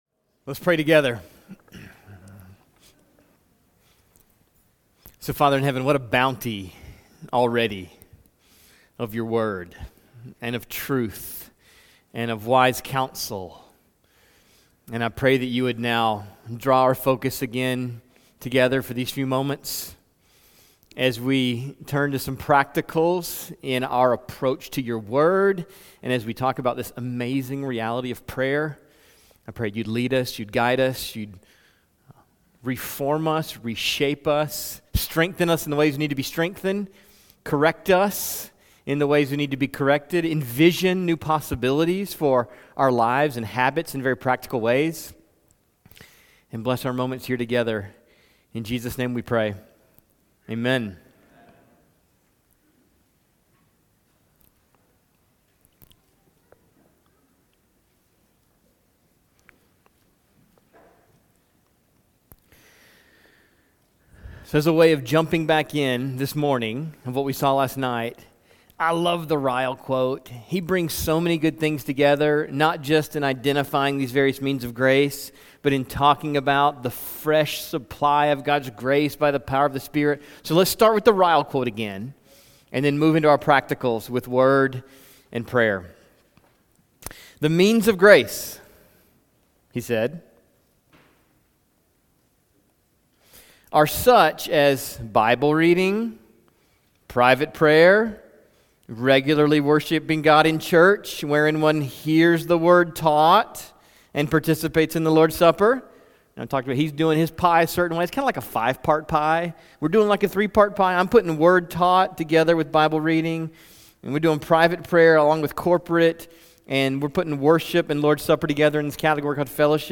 This message is part 2 of a three-part seminar on practicing the habits of grace in a hectic world.